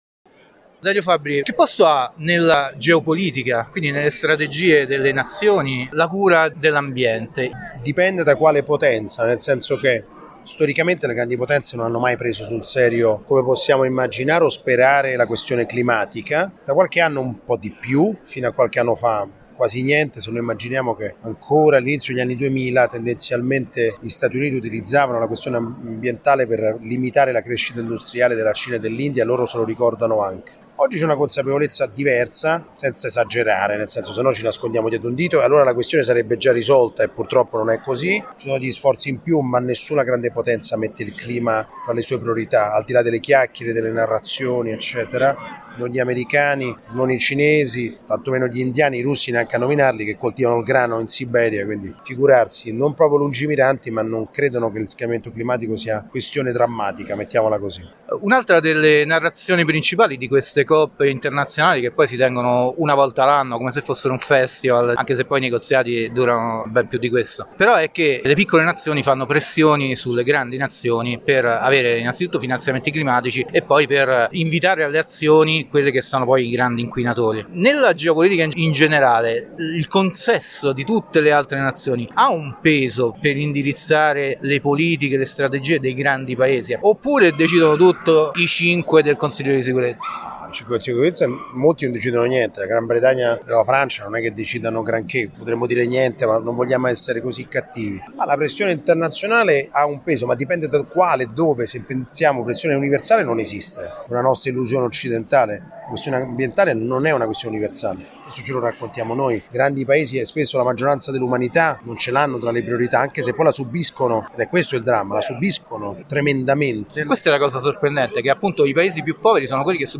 Che importanza danno Cina, Russia, USA, grandi e piccole potenze alle politiche climatiche? Intervista a Dario Fabbri.
Durante la recente Fiera Nazionale della Piccola e Media Editoria “Più libri più liberi” abbiamo incontrato Dario Fabbri, direttore della rivista Domino, in occasione dell’incontro di presentazione del nuovo numero intitolato “Fronti di guerra globale”.